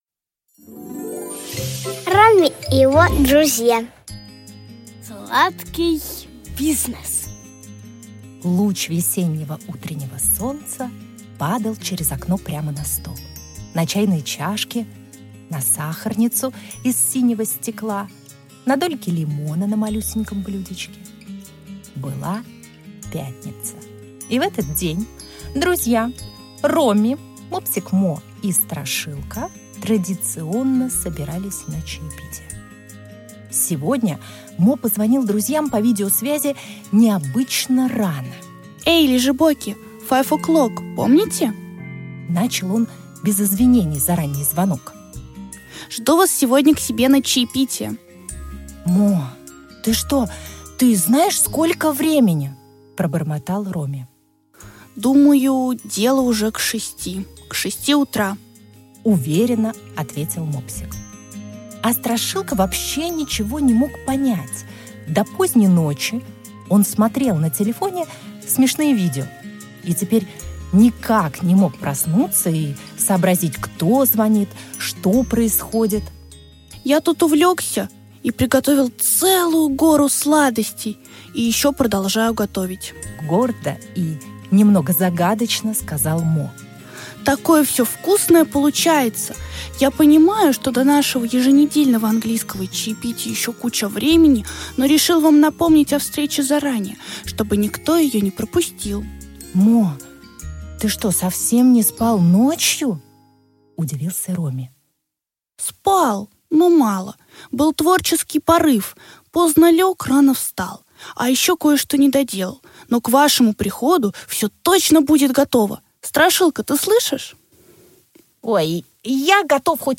Аудиокнига Ромми и его друзья. Сладкий бизнес | Библиотека аудиокниг